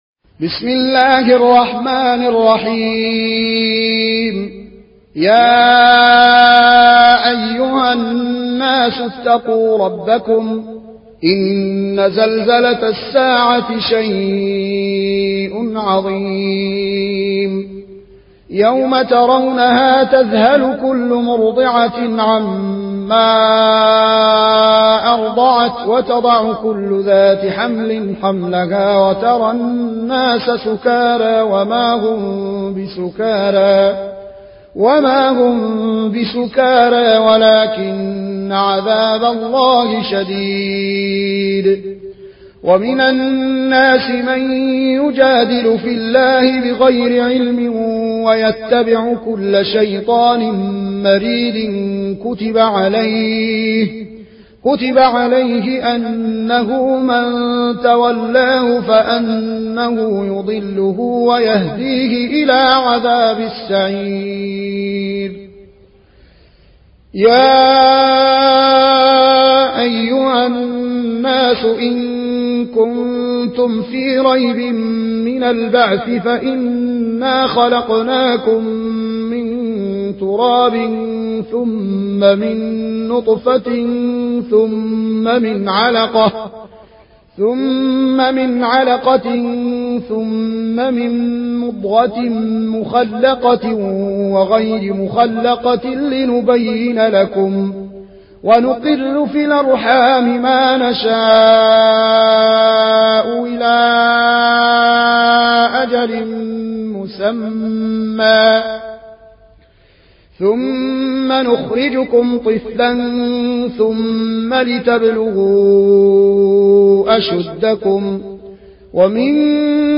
Riwayat Warch an Nafi